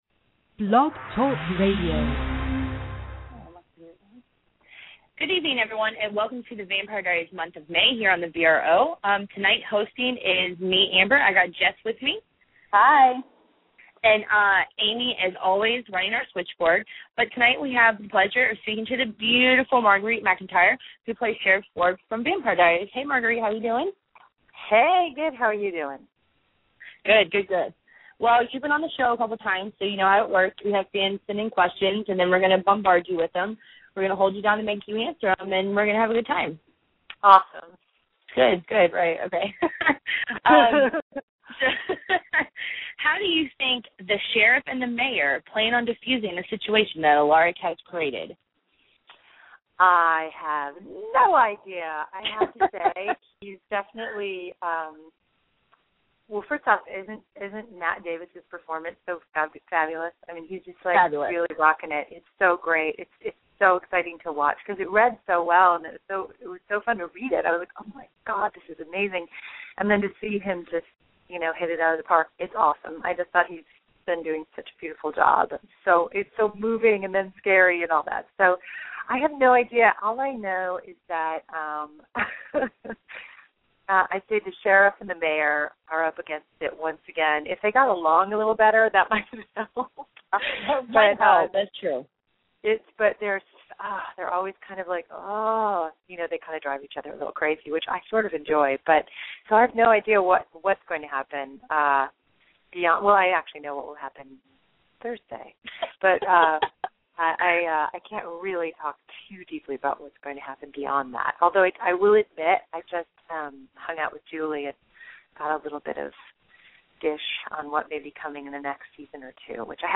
Marguerite MacIntyre "The Vampire Diaries" May 7th 2012 Interview